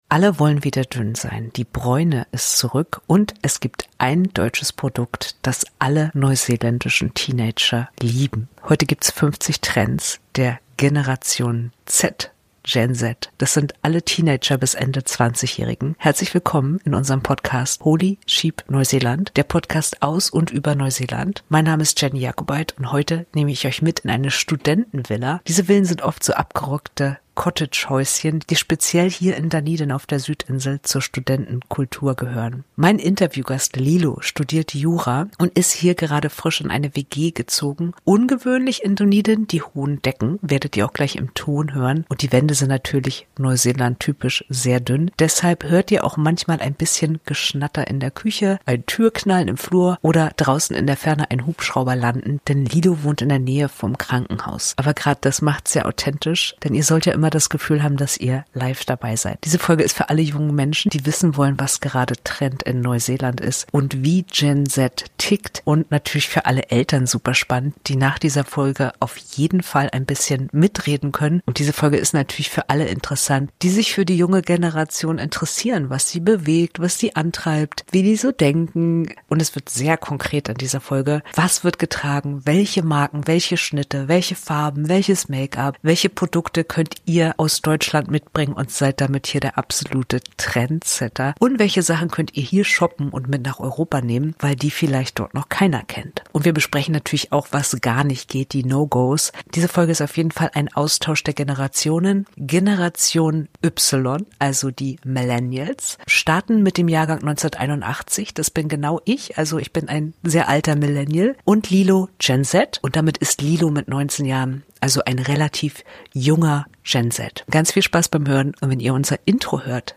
Ihr werdet das auch im Ton hören. Stimmen aus der Küche, Türen im Flur und irgendwo in der Ferne ein Hubschrauber vom nahegelegenen Krankenhaus. Genau diese Atmosphäre gehört zum Studentenleben hier und macht das Gespräch sehr nah und authentisch. Wir sprechen sehr konkret über Trends unter jungen Leuten in Neuseeland.